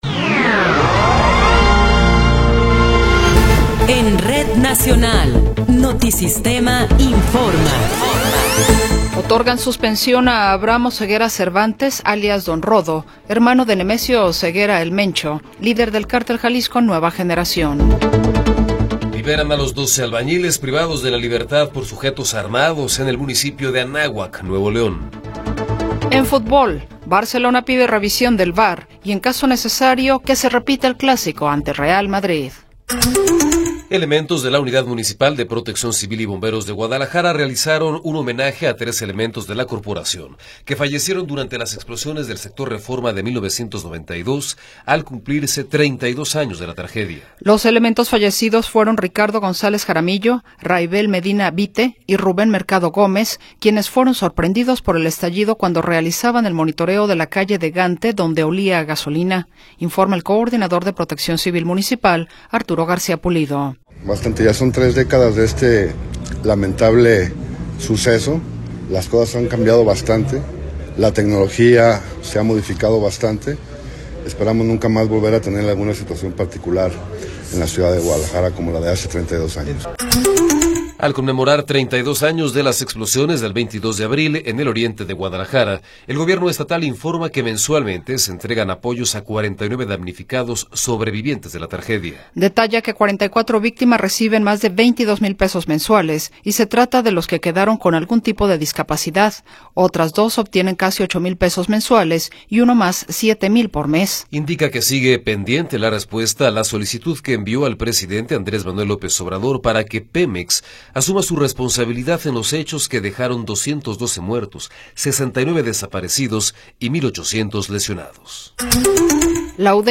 Noticiero 14 hrs. – 22 de Abril de 2024